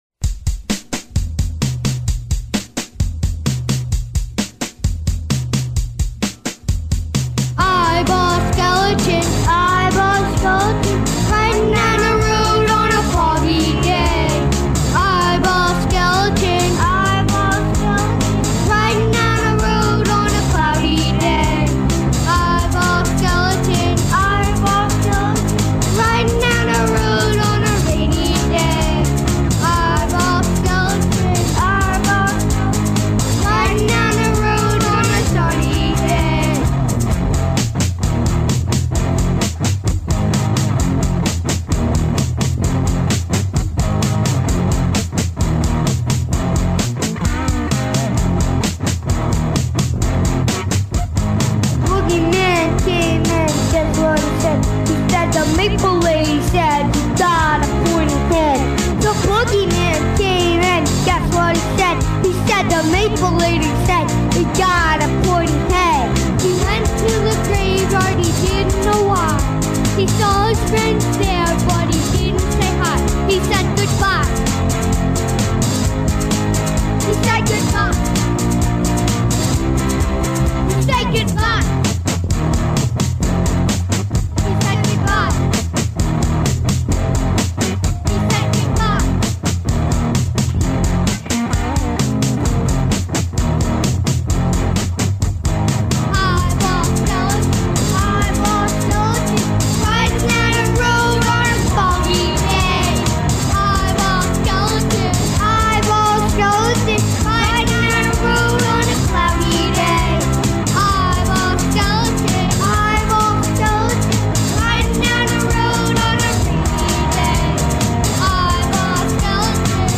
It was a crude home recording with a cheesy drum machine, but it radiated real charm.